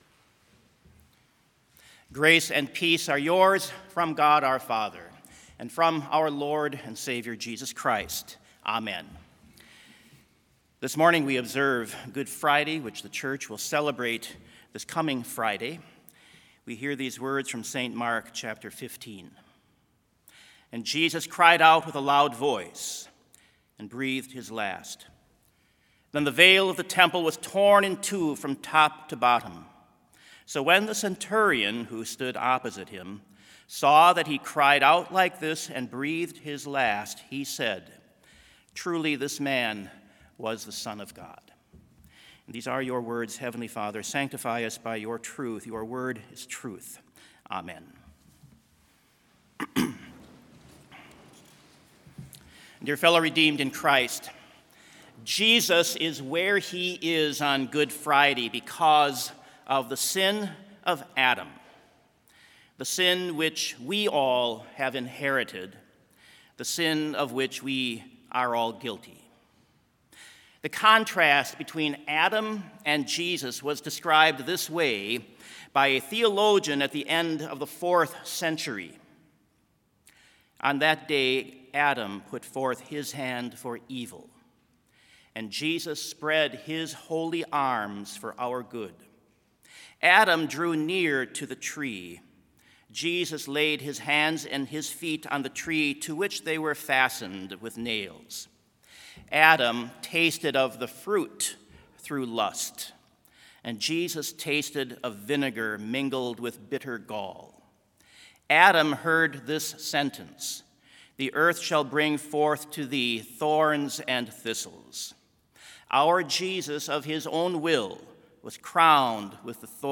Complete Service
This Chapel Service was held in Trinity Chapel at Bethany Lutheran College on Tuesday, April 12, 2022, at 10 a.m. Page and hymn numbers are from the Evangelical Lutheran Hymnary.